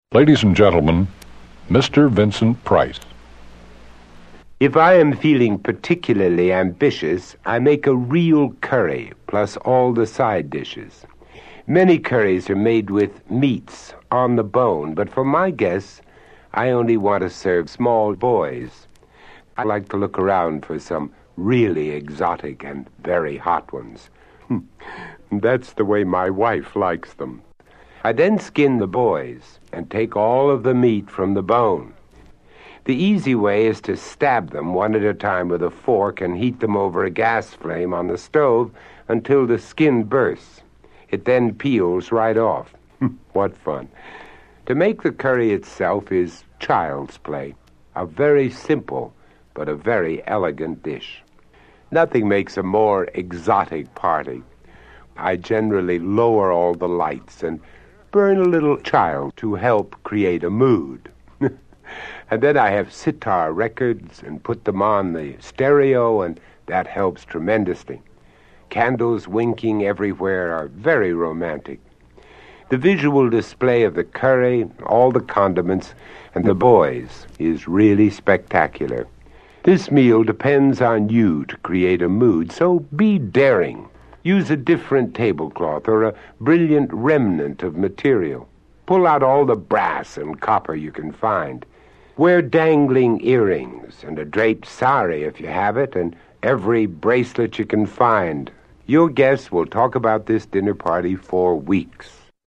Collage of Celebrity Speech
This is a gallery of collage derived from the utterings of media celebrities, created by artists from around the planet.